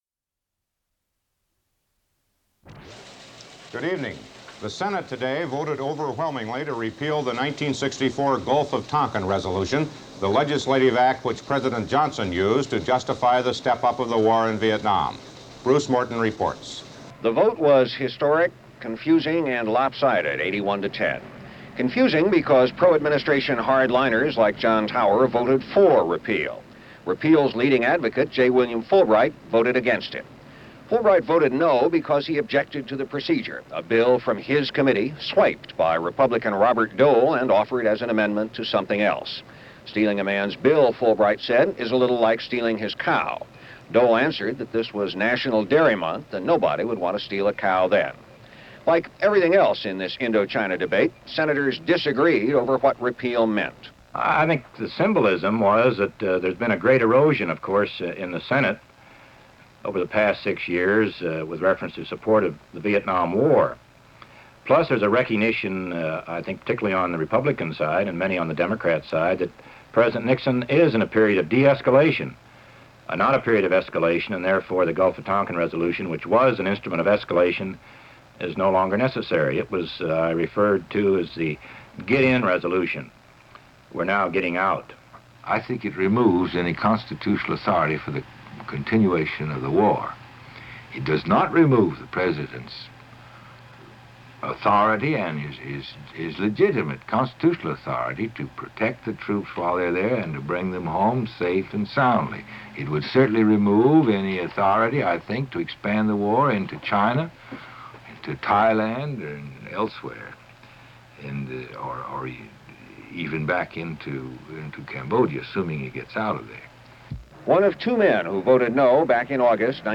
Part of Radio Spots on the Repeal of the Gulf of Tonkin Resolution